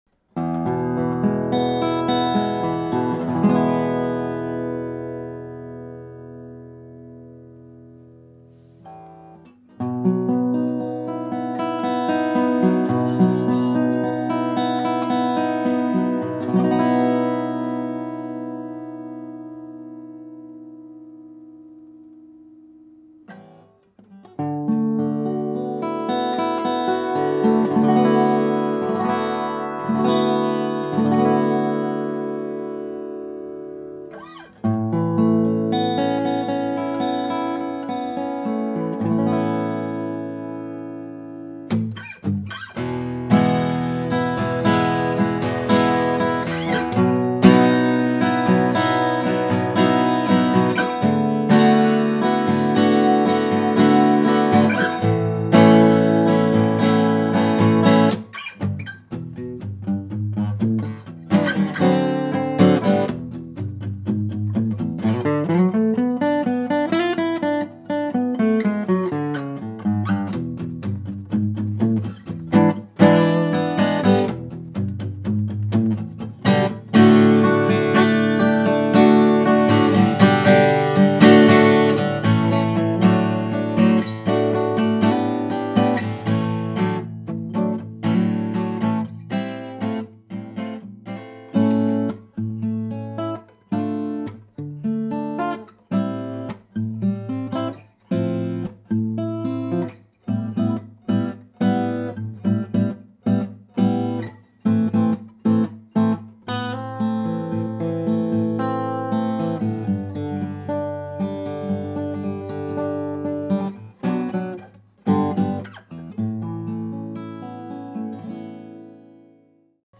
Lowden F50c Brazilian Rosewood - Sinker Redwood
Lowden F50c Mastergrade Brazilian Rosewood - Sinker Redwood.
Sound-impression-Lowden-F50c-Brazilain.mp3